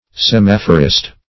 Semaphorist \Se*maph"o*rist\, n. One who manages or operates a semaphore.